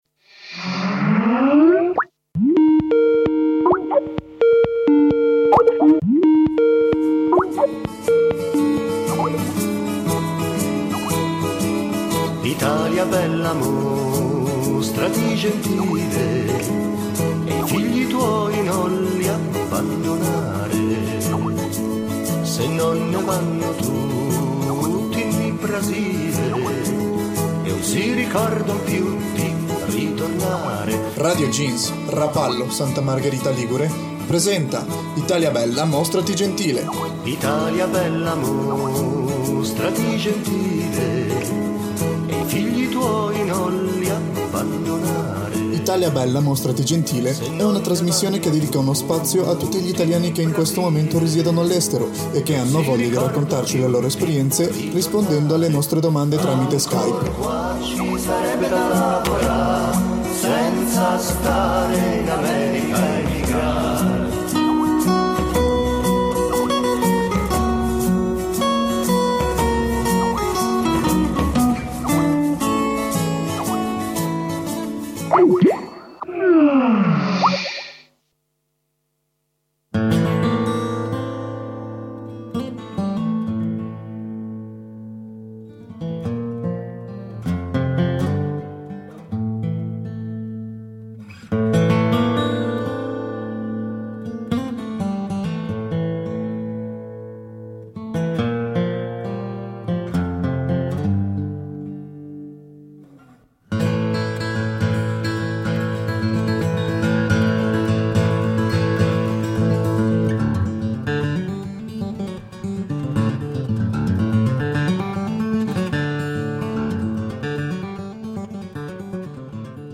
Ascoltiamo questa nuova narrazione di un paese peraltro travolto da una grave crisi economica simile a quella italiana. Vicende personali e cambiamenti epocali si stagliano dietro alle parole del nostro intervistato che attraversa la storia delicata e fragile dell'europa mediterranea.